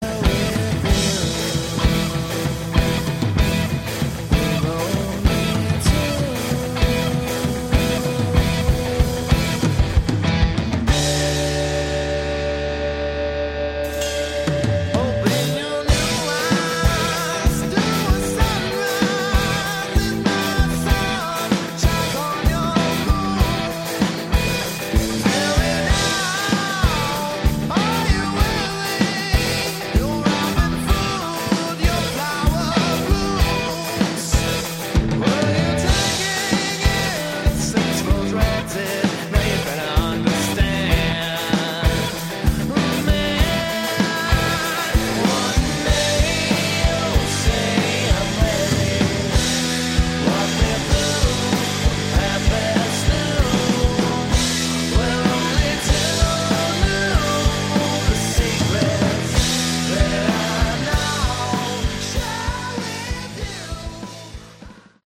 Category: Hard Rock
lead vocals, bass
guitars, keyboards, backing vocals
drums